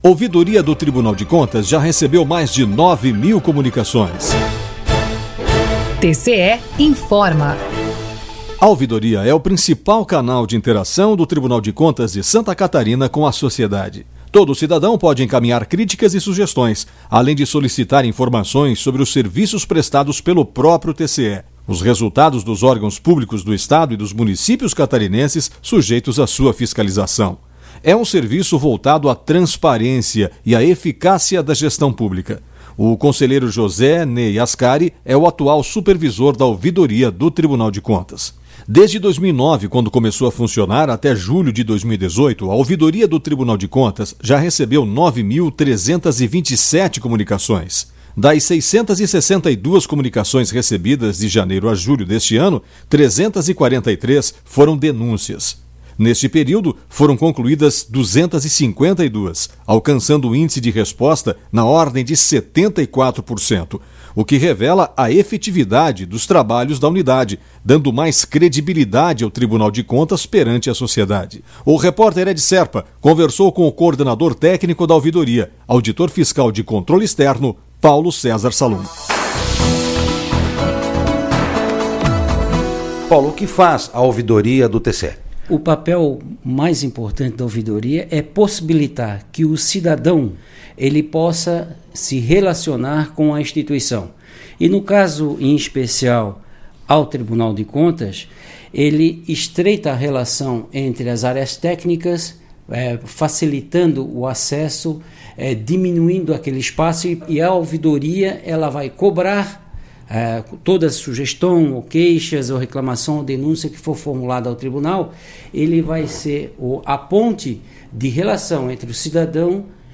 Rádio TCESC - Ouvidoria_1.mp3